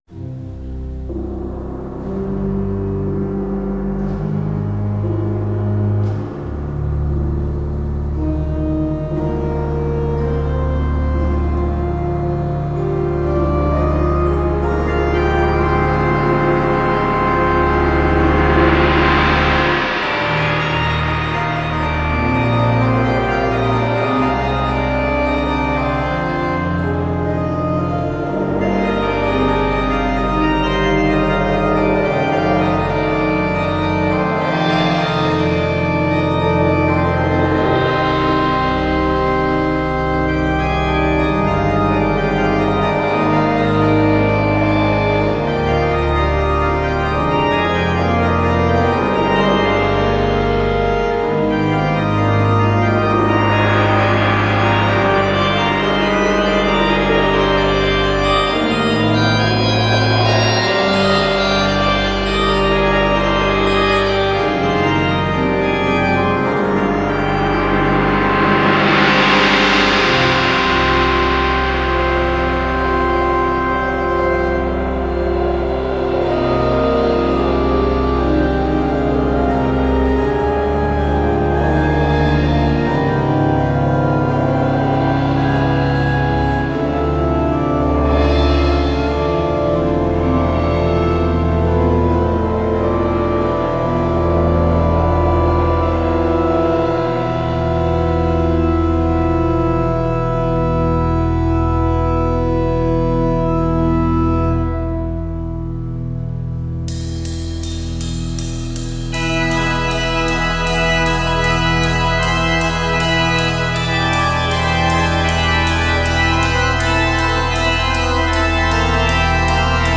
Werke für Orgel & Schlagwerk